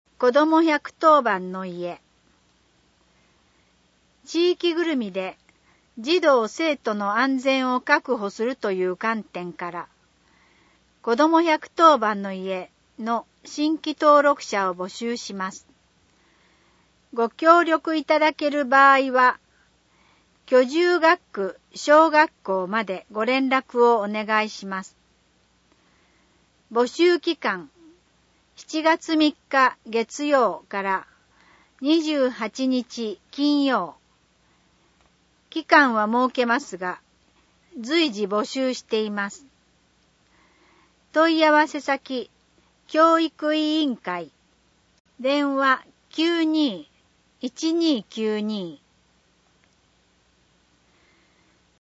声の広報とよあけは、視覚障がい者のみなさんを対象に、種々の情報提供を行っている草笛の会のご協力で、市からのお知らせなどが掲載された広報紙などを音訳していただき、音声データとして提供いたします。